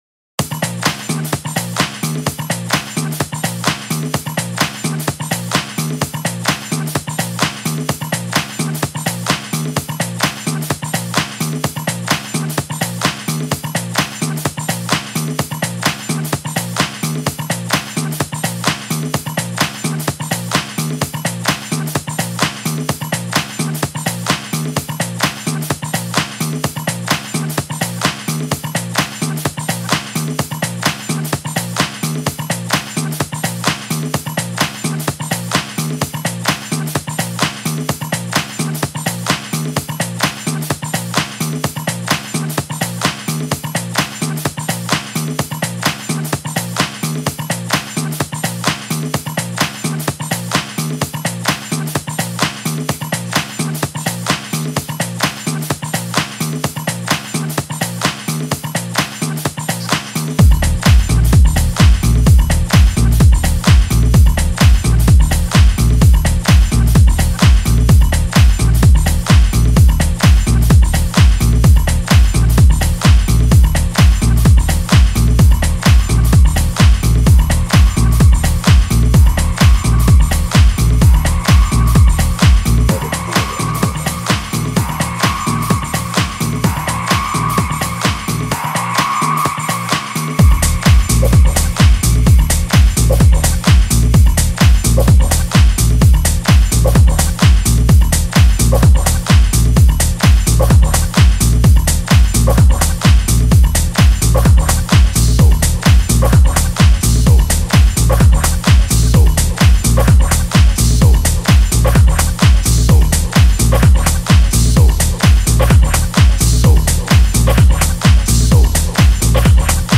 pause_music_02.mp3